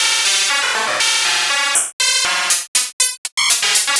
FM Hitter 02.wav